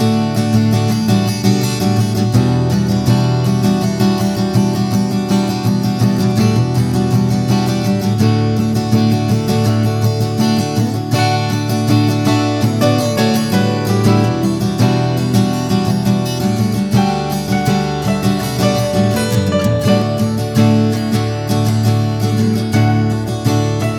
Male Key of G Pop (1970s) 3:38 Buy £1.50